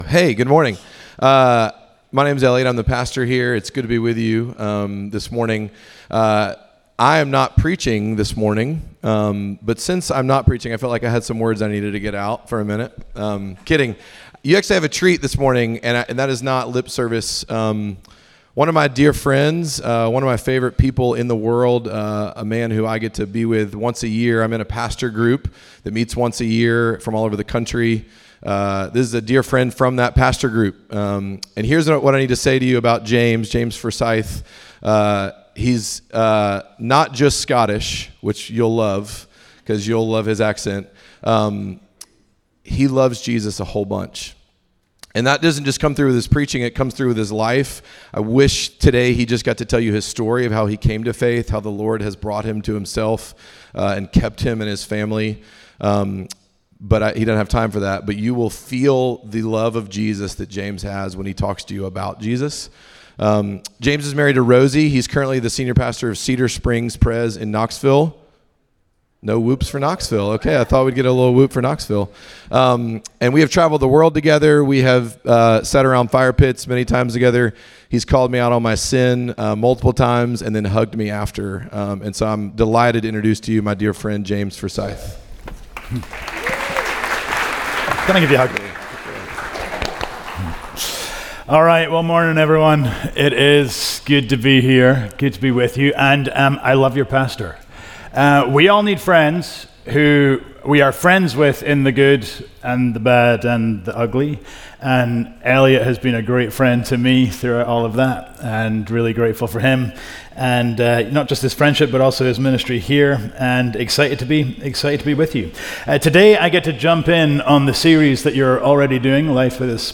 Midtown Fellowship 12 South Sermons Life By The Spirit – Patience Jun 22 2025 | 00:38:18 1x 00:00 / 00:38:18 Subscribe Share Apple Podcasts Spotify Overcast RSS Feed Share Link Embed